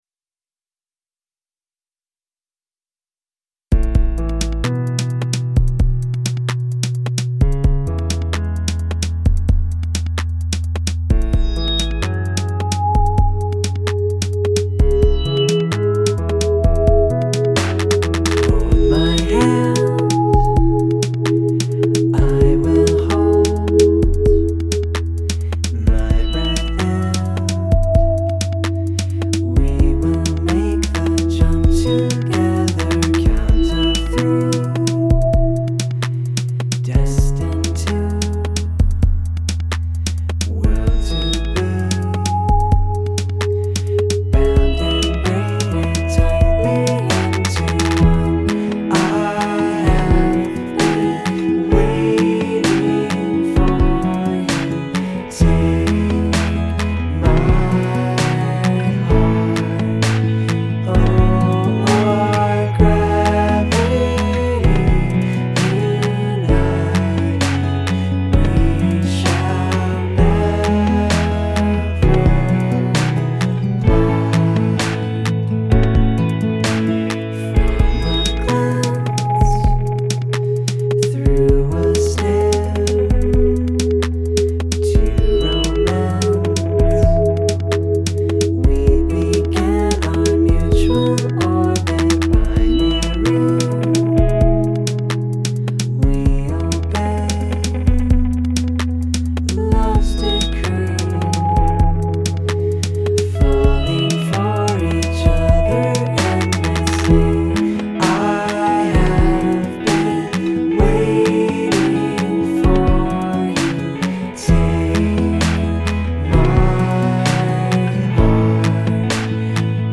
The synth sound on this reminds me of Helicopter.
Nice beats.
I hear a vocal part that sits between the piano bits in the verse, and comes in on the off beats of the chorus, in between the big chords.
I really like the chord voicings you're using and the rhythmic turnaround at the end of the verse pattern. I love the snare on the way into the chorus, too. Half-time beats rule, and the chorus is made really cool by virtue of that great beat.
The words of the verse follow the piano melody you've got there, the lines of the chorus alternate between falling on every other beat evenly (slow but kind of deliberate and i like that) and following the piano.